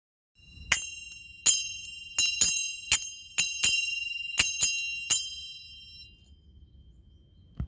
真鍮指ベル
ダンサーが指に挟み鳴らしながら踊ります。
素材： 真鍮